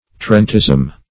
Tarentism \Tar"ent*ism\, n.